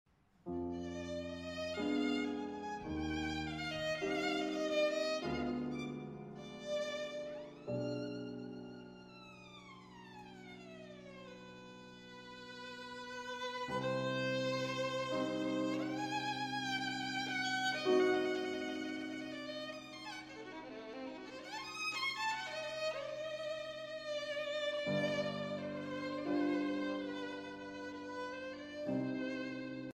These samples were all recorded using Borman Instruments.
Violin
Live performance on her Borman of Sarasate - Zigeunerweisen for the Canadian Broadcasting Company.